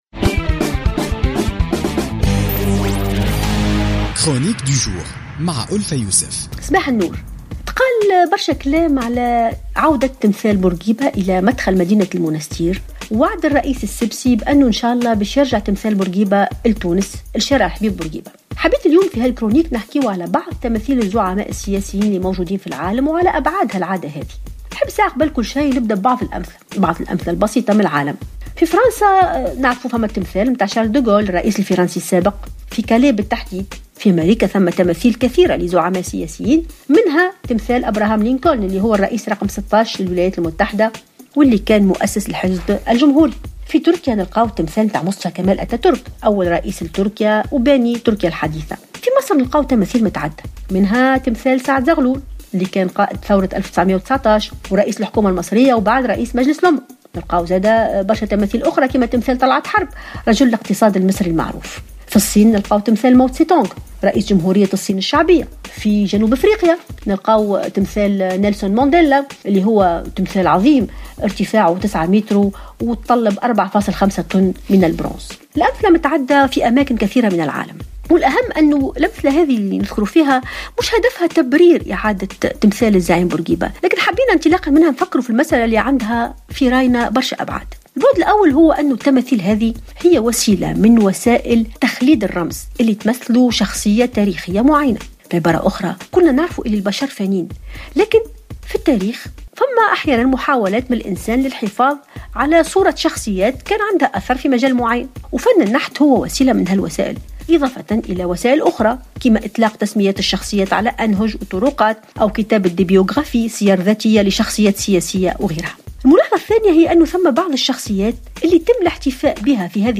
تطرقت الباحثة ألفة يوسف في افتتاحية اليوم الجمعة 25 مارس 2016 إلى موضوع اعادة تمثال الزعيم بورقيبة إلى مدخل مدينة المنستير وإلى شارع الحبيب بورقيبة مستحضرة في ذلك أمثلة لعادة وضع تماثيل الزعماء في عواصم العالم .